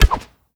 pgs/Assets/Audio/Custom/Combat/Shoot.wav at master
Shoot.wav